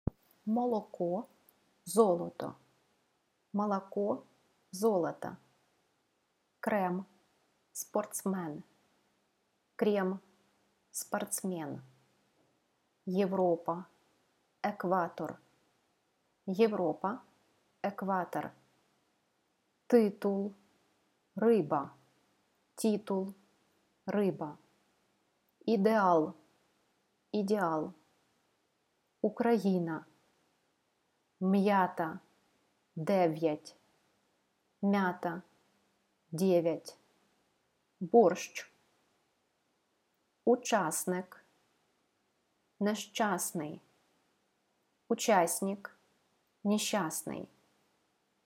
Ukrainian vs. Russian sounds (623 KB)